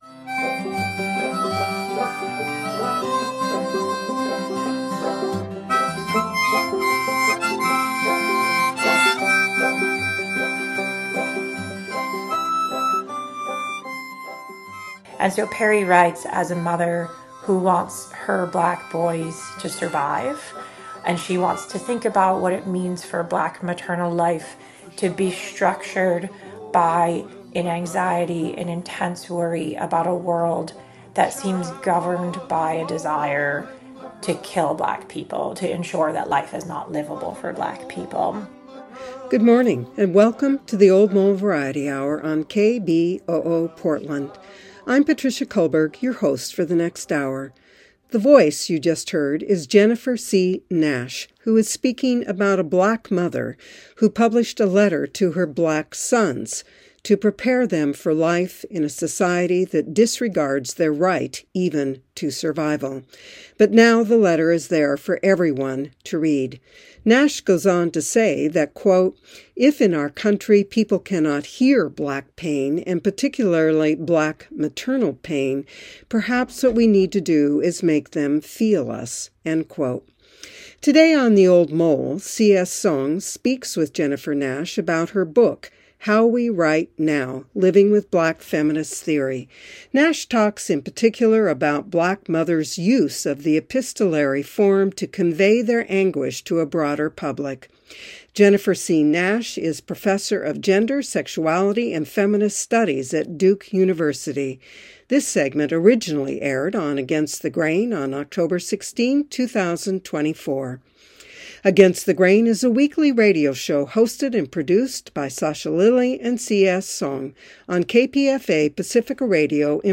Views, Reviews, and Interviews